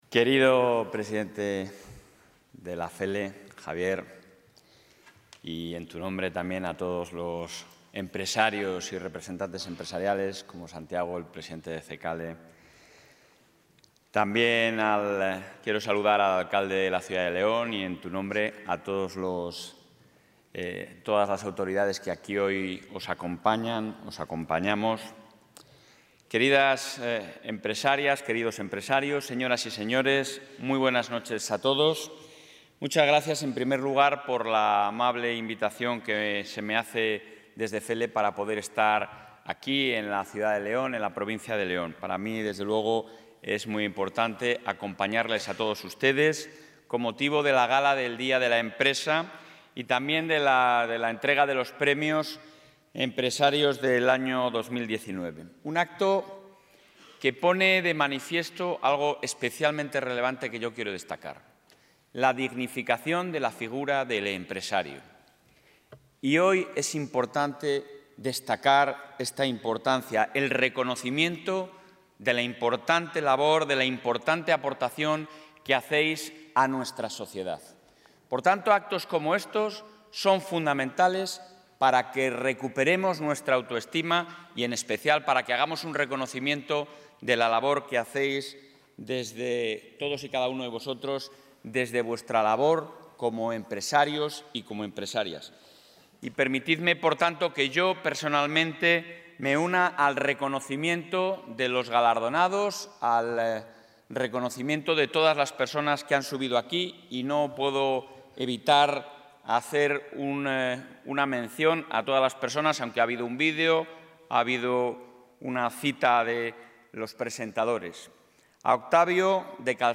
Intervención presidente.
Fernández Mañueco ha clausurado esta noche la Gala del Día de la Empresa organizada por FELE, donde se ha hecho entrega de los Premios Empresario del Año 2019